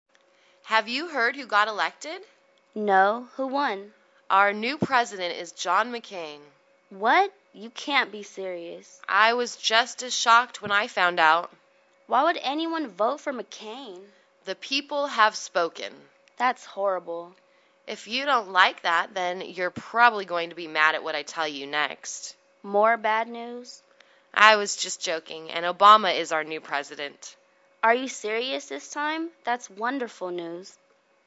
英语情景对话：Disappointed at the Result(3) 听力文件下载—在线英语听力室